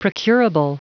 Prononciation du mot procurable en anglais (fichier audio)
Prononciation du mot : procurable